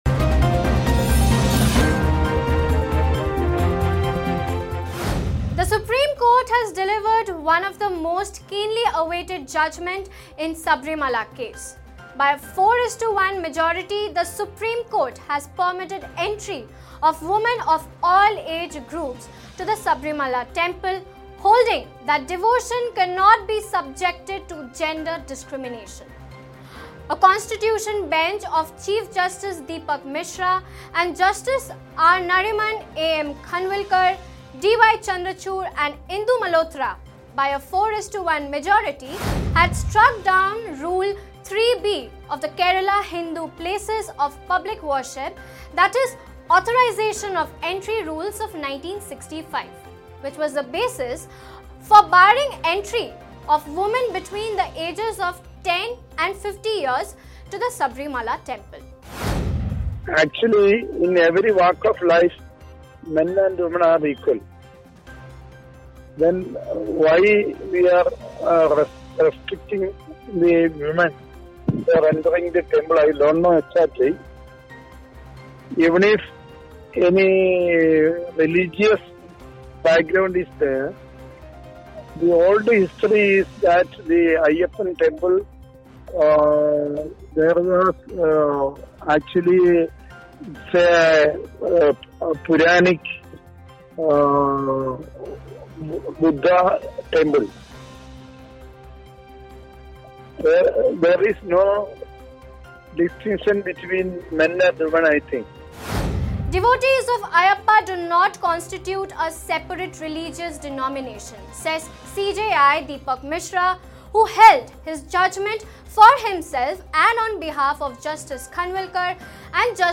News Report / Supreme Court uplifts ban on women entry in Sabarimala temple